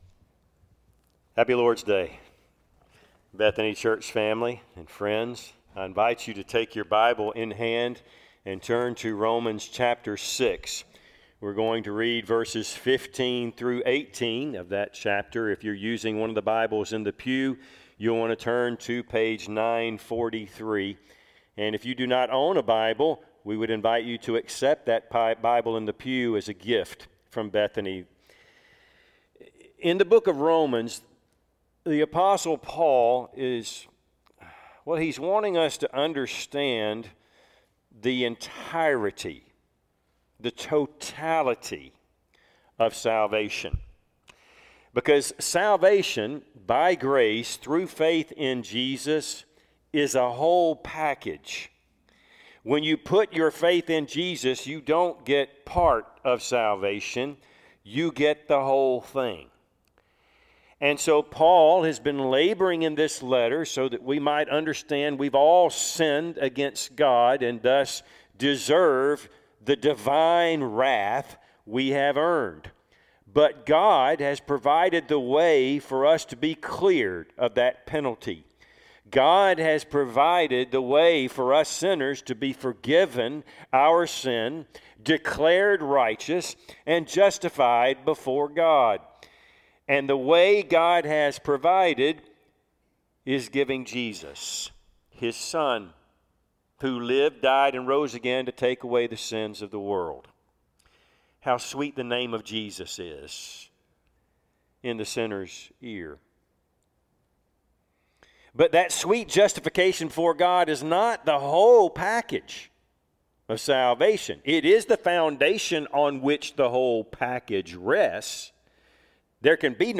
Service Type: Sunday AM Topics: Christian living , justification , Sanctification